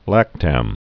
(lăktăm)